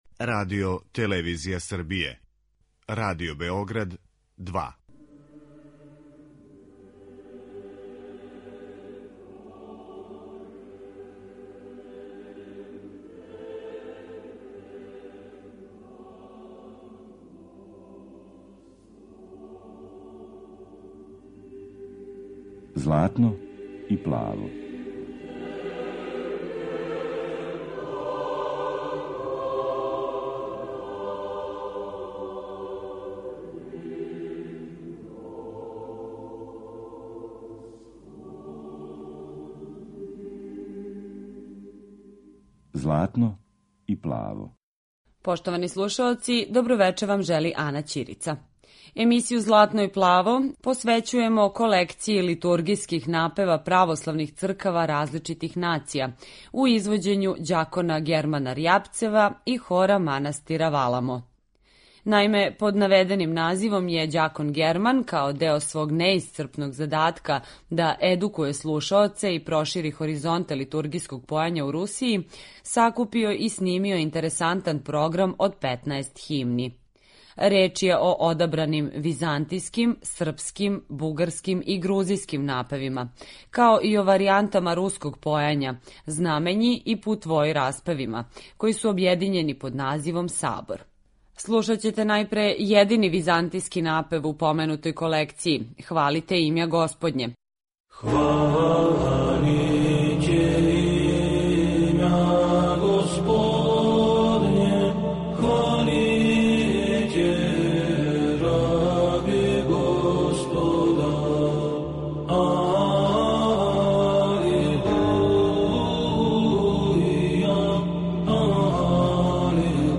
Химне различитих нација
Емитујемо избор из колекције литургијских напева православних цркава различитих нација
византијски, српски, бугарски, руски и грузијски напеви